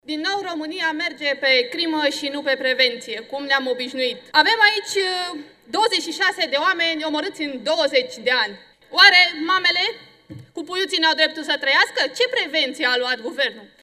Codruța Maria Corcheș, de la POT: „România merge pe crimă și nu pe prevenție”